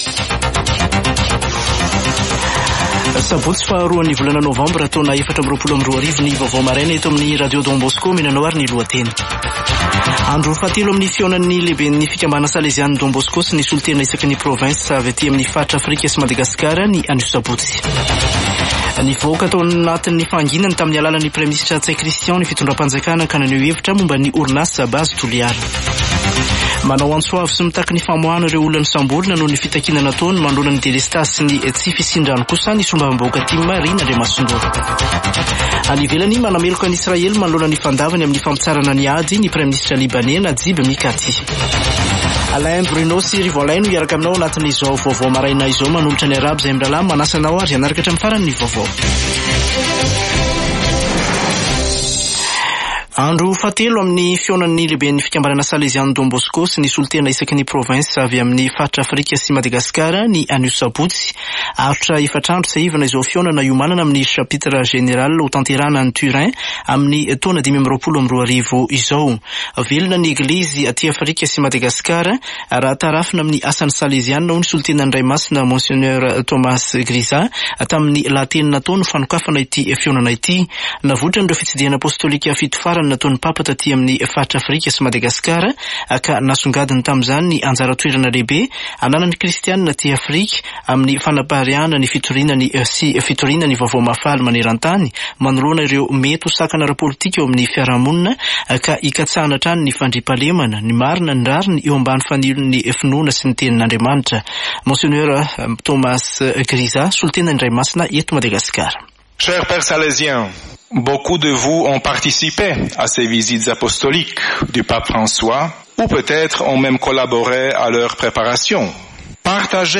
[Vaovao maraina] Sabotsy 2 novambra 2024